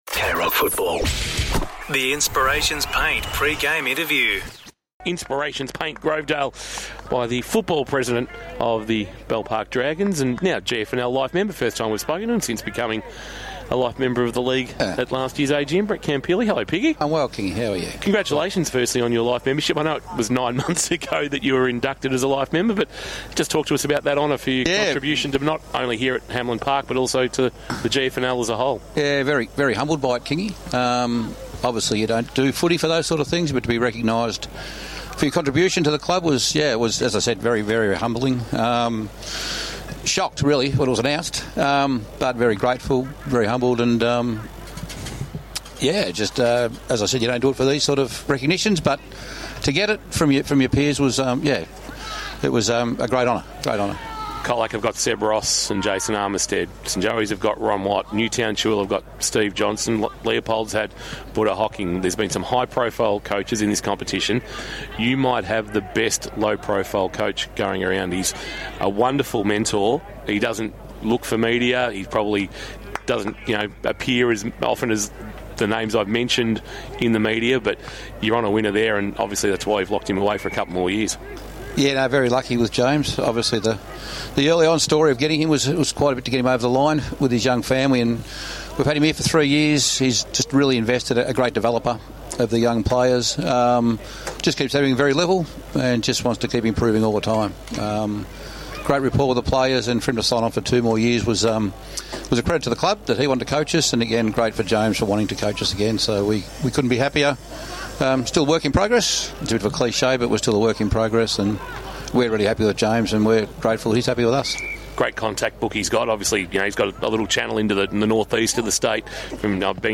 2025 - GFNL - Round 15 - Bell Park vs. Colac: Pre-match interview